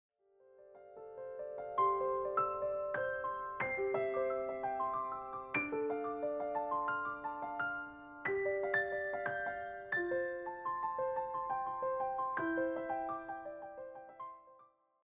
a consistent, relaxed romantic mood